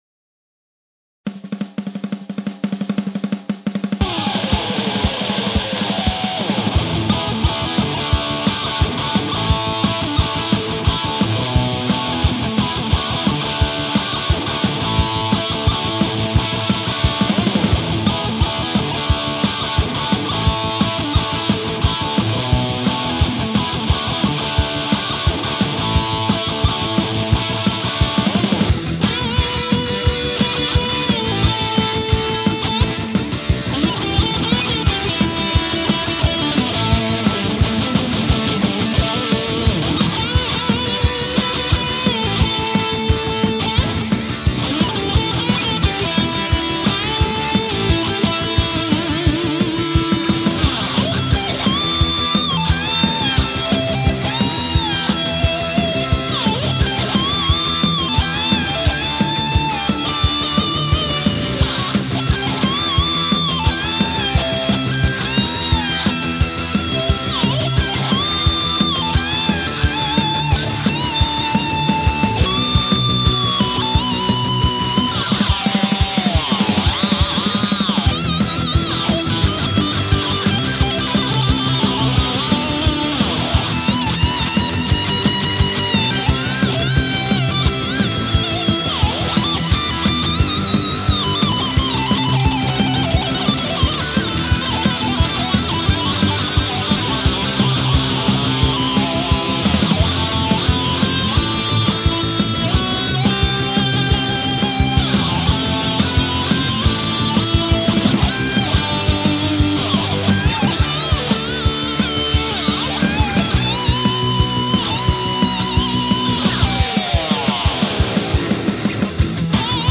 Darum benutze ich Hintergrundmusik, wenn ich spiele.